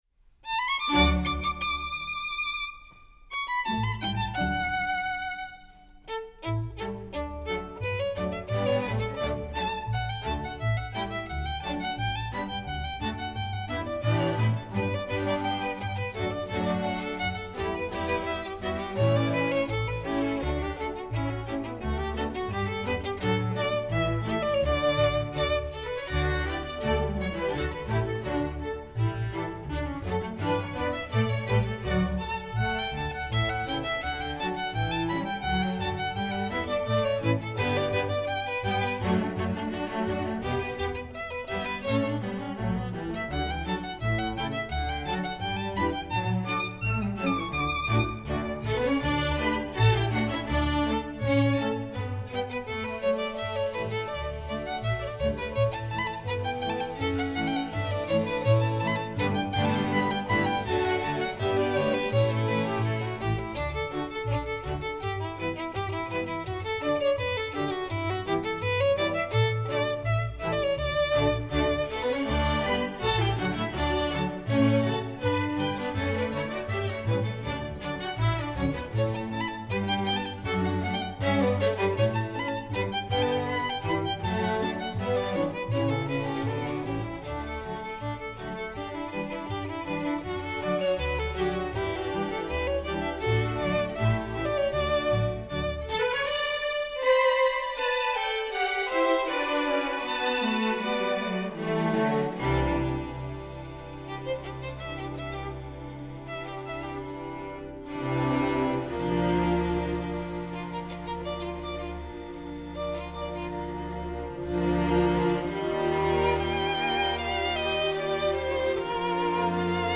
instructional, concert, classical, light concert
Solo violin part: